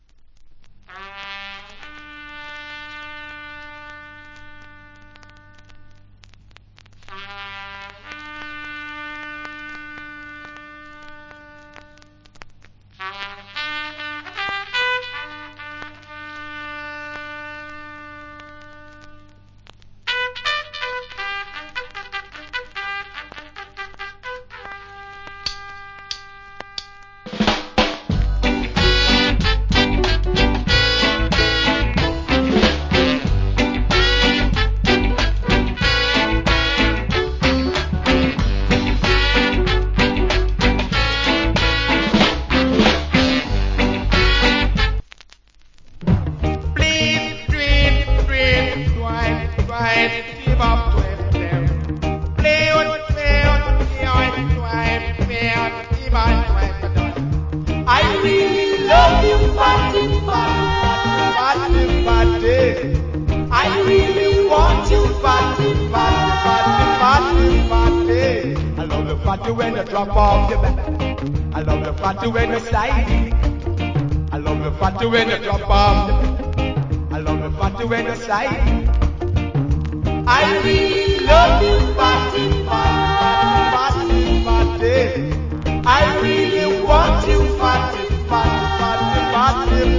Killer Early Reggae Inst.